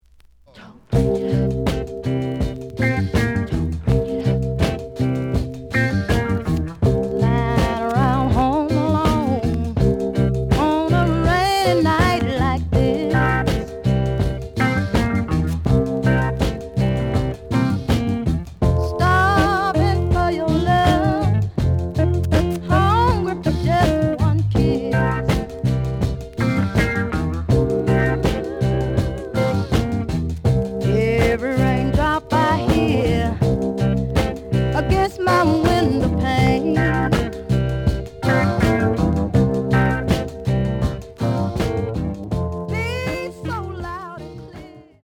The audio sample is recorded from the actual item.
●Genre: Soul, 70's Soul
Some noise on parts of A side.